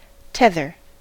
tether: Wikimedia Commons US English Pronunciations
En-us-tether.WAV